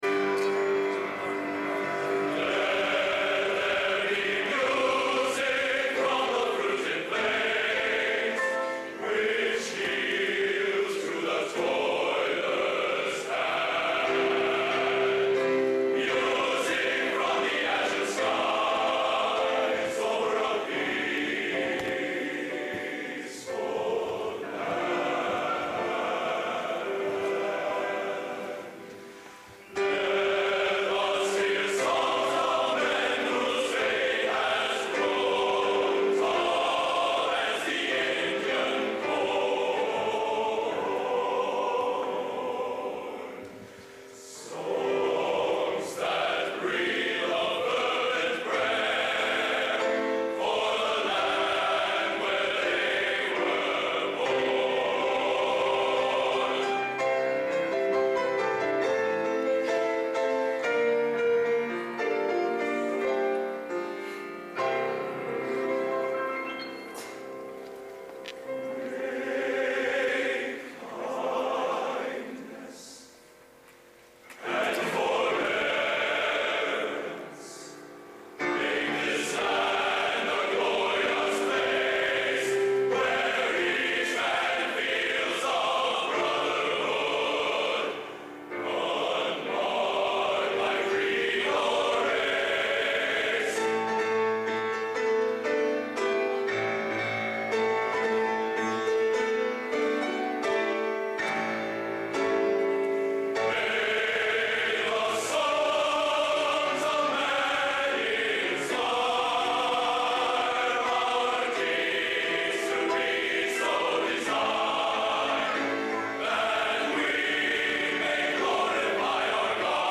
Location: Purdue Memorial Union, West Lafayette, Indiana
Genre: Traditional | Type: